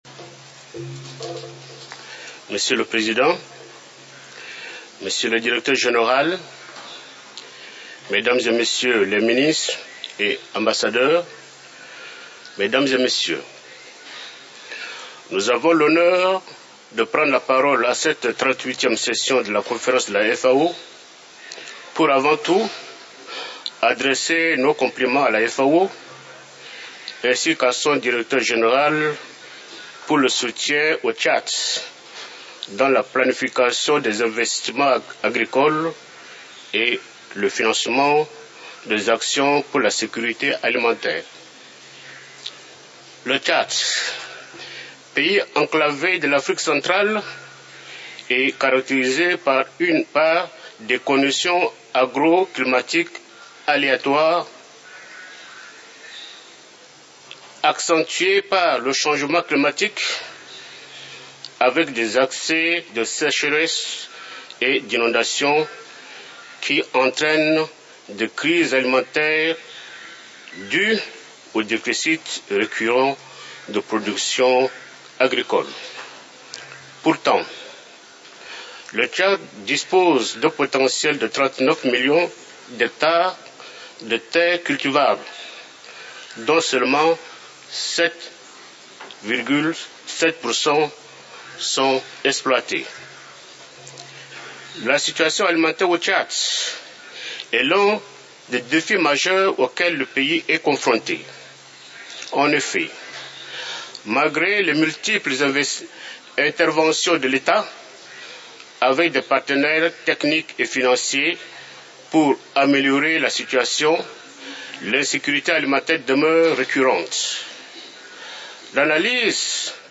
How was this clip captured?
FAO Conference